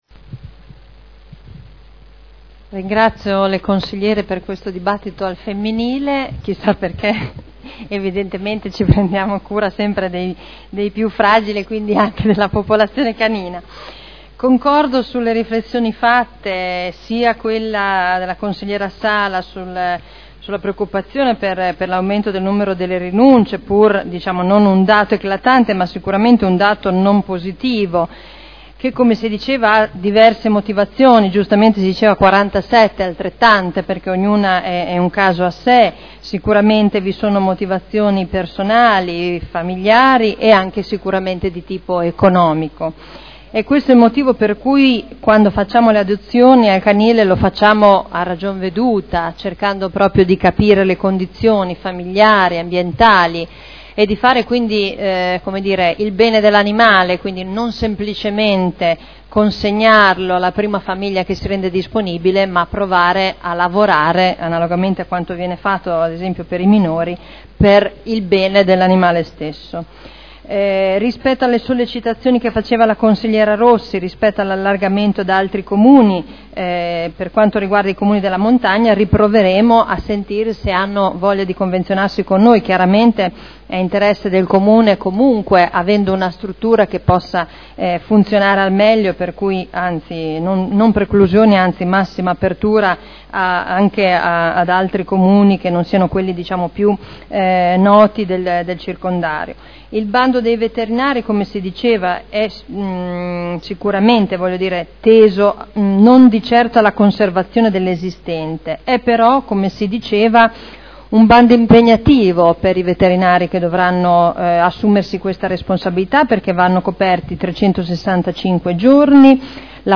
Seduta del 01/10/2012 Conclusioni a dibattito.